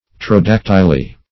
Pterodactyli \Pter`o*dac"ty*li\, n. pl. [NL.] (Paleon.)